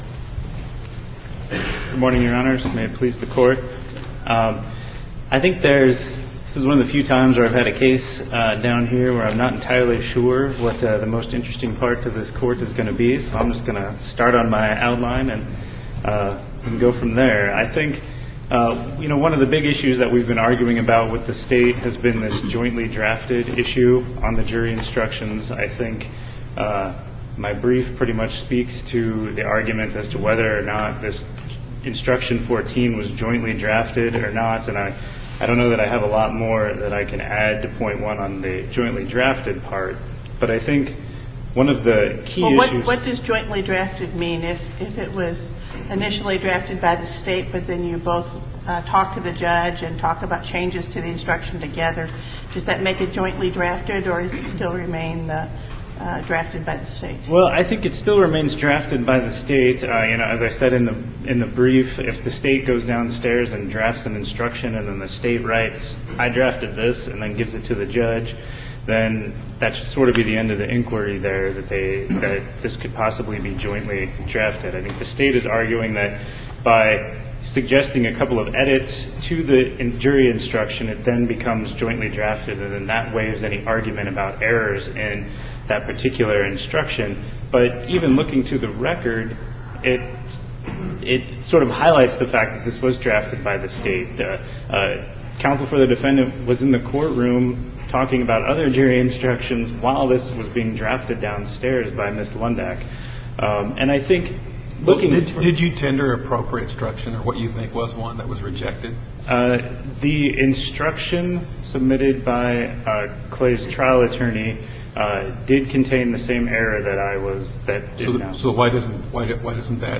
MP3 audio file of oral arguments in SC96016